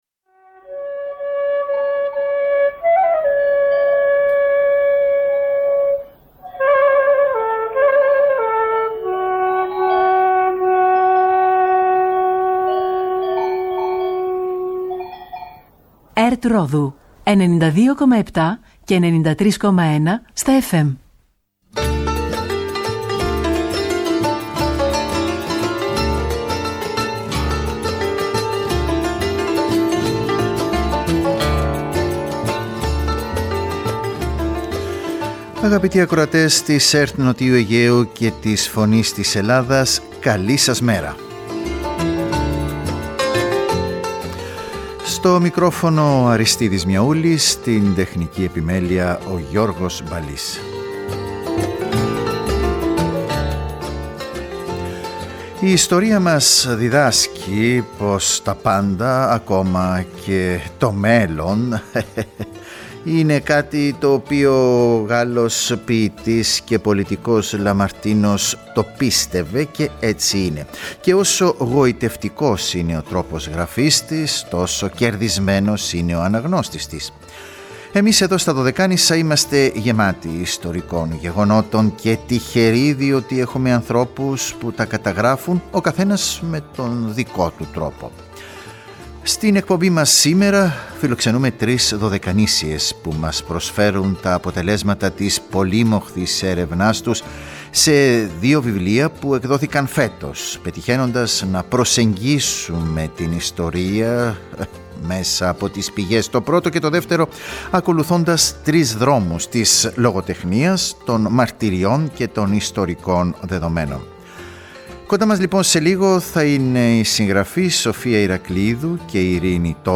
Τρείς Δωδεκανήσιες μας μιλούν για τα βιβλία τους που σηματοδοτούν εποχές και κεφάλαια της Δωδεκανησιακής ιστορίας ταξιδεύοντας τους αναγνώστες με έναν ιδιαίτερο ενδιαφέροντα τρόπο, συμβάλλοντας στη γνώση μας με τρόπο συναρπαστικό και πρωτότυπο.